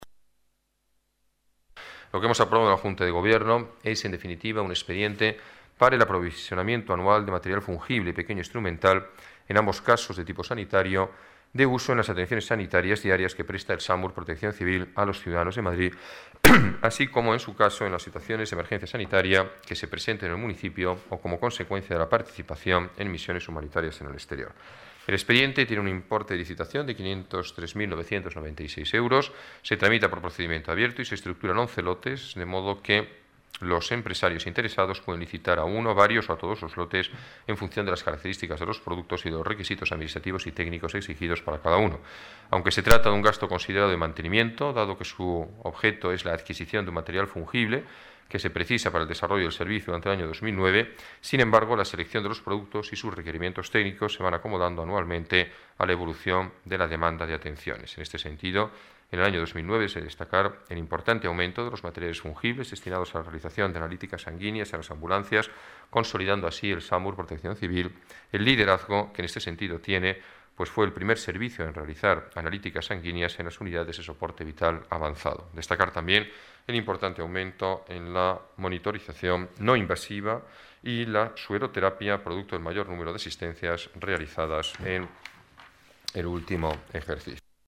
Nueva ventana:Declaraciones del alcalde de Madrid, Alberto Ruiz-Gallardón: material del SAMUR